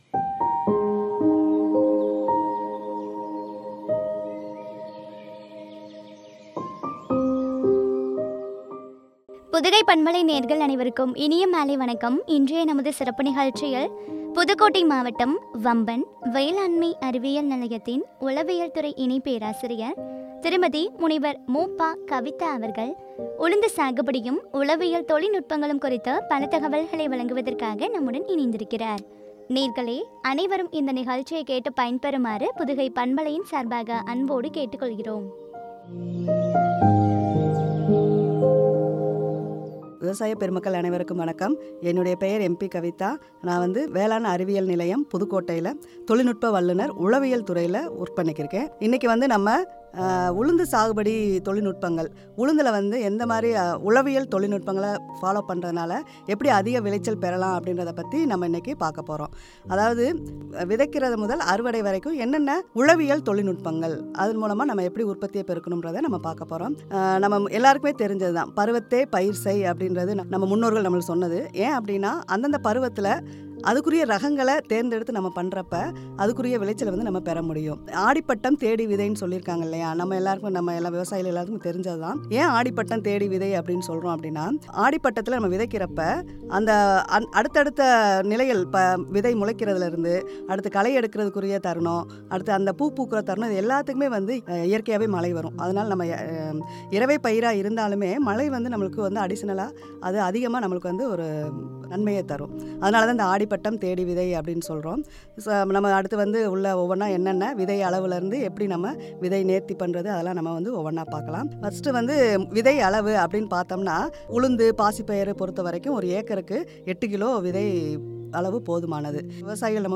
உழவியல் தொழில்நுட்பங்களும் என்ற தலைப்பில் வழங்கிய உரையாடல்.